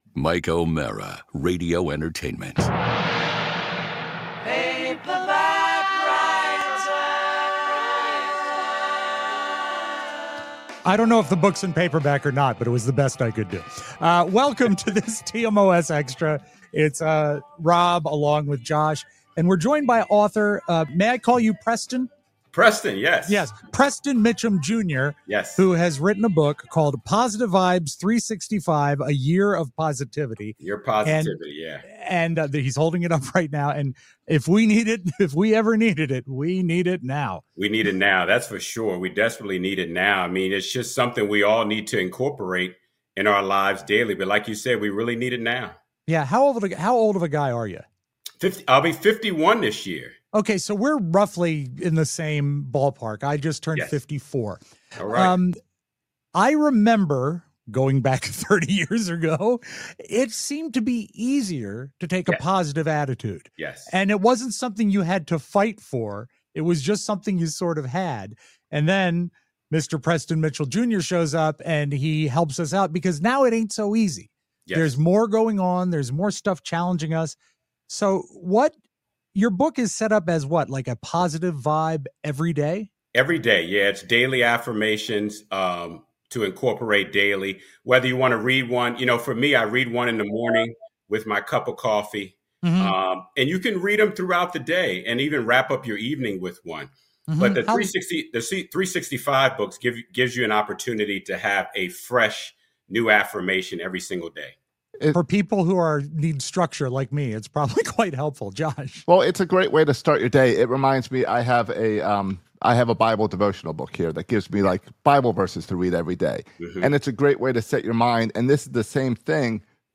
Plus, we explore the important work of the PMJ Foundation and how it’s spreading positivity far beyond the page. Don't miss this uplifting conversation!